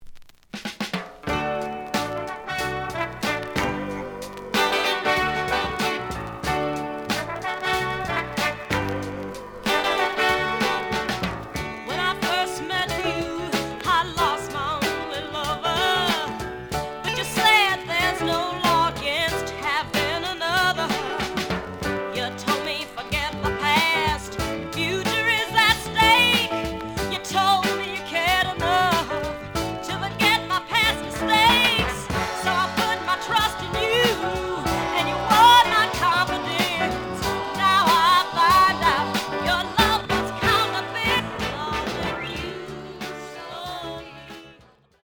The audio sample is recorded from the actual item.
●Genre: Soul, 60's Soul